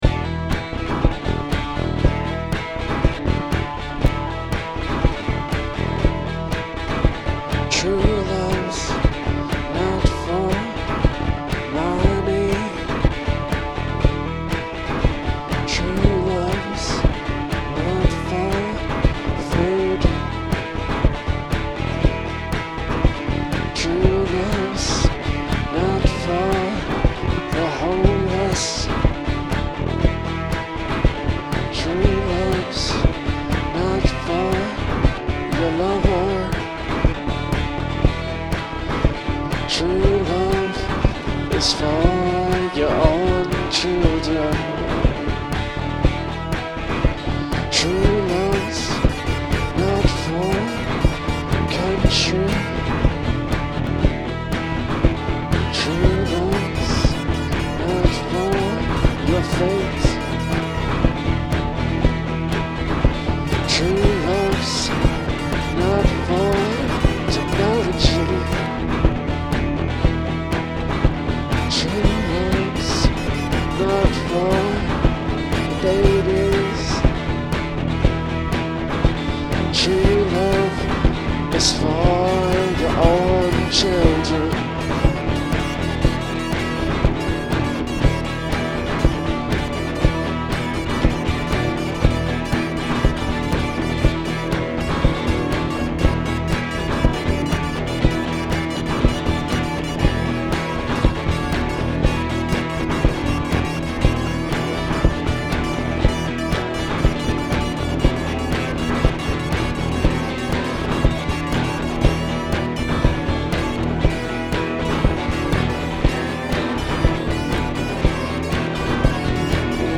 The guitars sounded much too thin and not at all what I was going for.
Anyway, I decided to fill it out a bit and I'm pretty happy with how that turned out.
I think the cacophony is pretty good, but more drums never hurt an indie dance anthem.